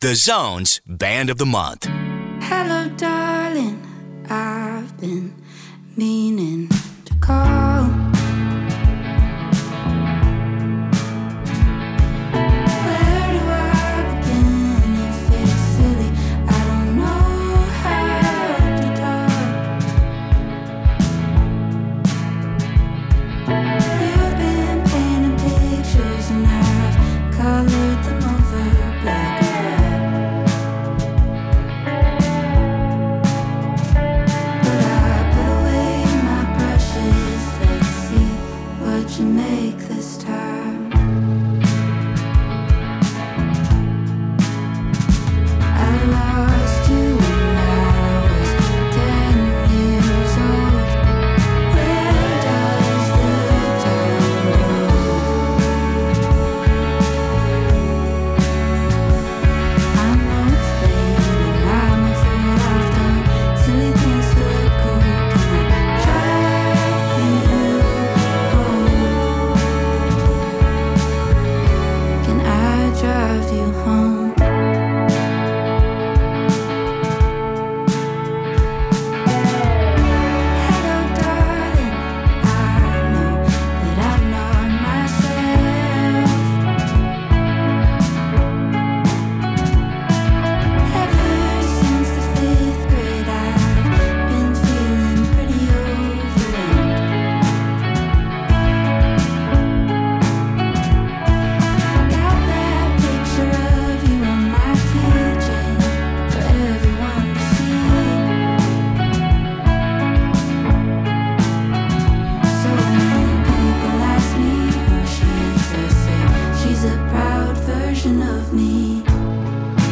vocals, guitar
guitar, bass, synth
violin, piano
indie
and hope through relatable lyrics and emotive soundscapes.